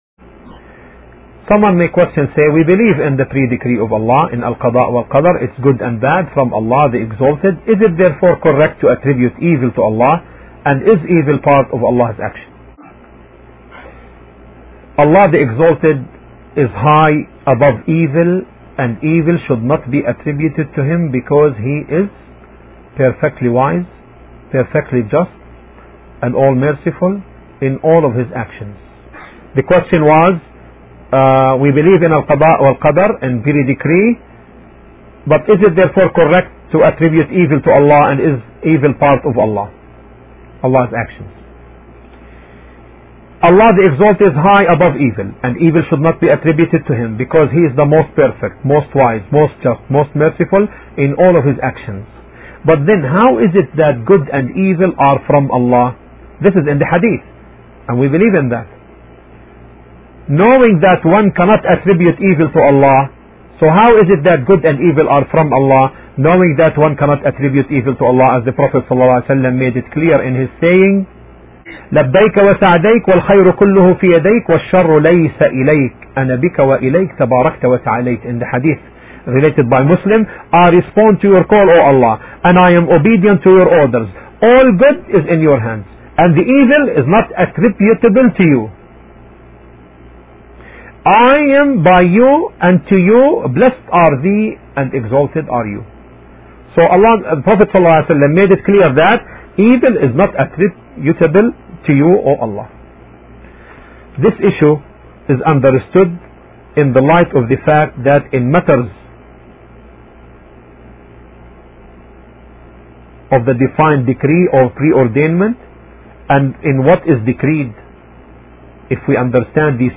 The lecturer answers an important question: